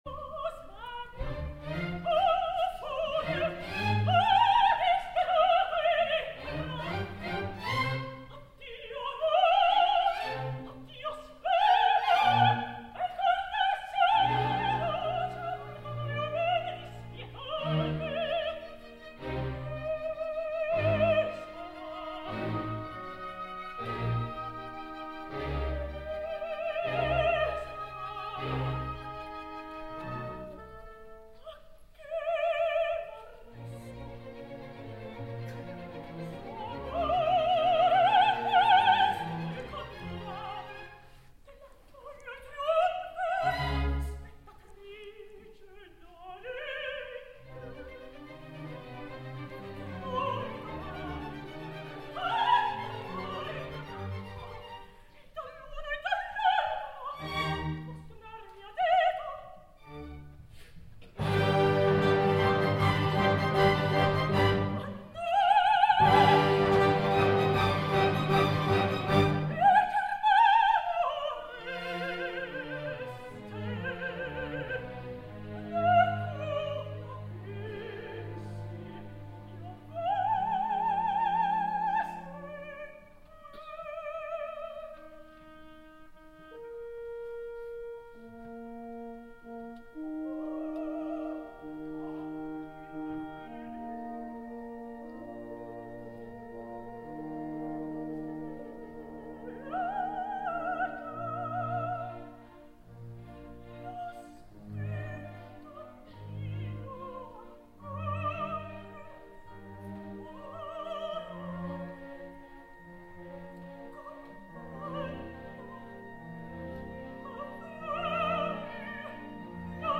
I del tercer acte escoltem l’explosiva “D’Oreste, d’Ajace” a càrrec de la magnífica Malin Byström que comet la gosadia d’acabar la seva ària de bravura amb un sobreagut, quelcom fora d’estil que sempre he criticat i amb ella no faré una excepció per molt que m’agradi.
ROH Covent Garden de Londres, 15 de novembre de 2014